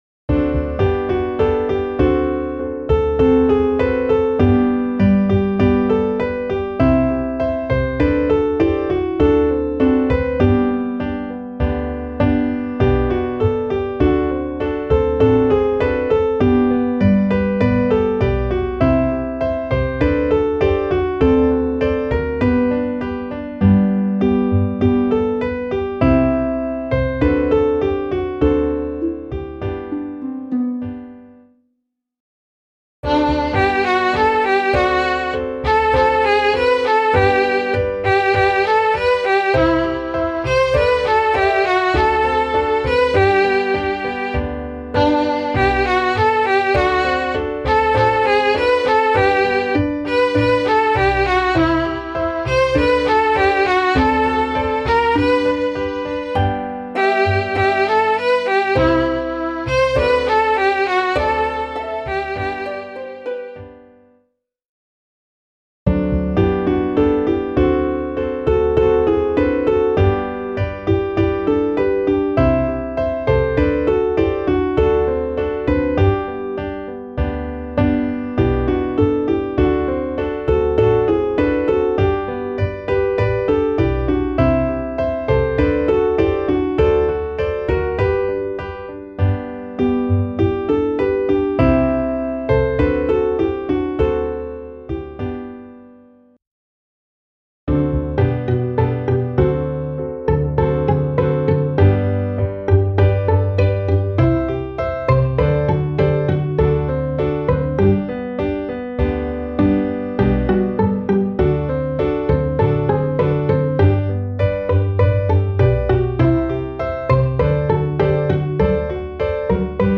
Klaviersatz
midi_kleine-ballade_klavier_320.mp3